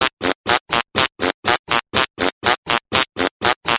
Categoria Engraçados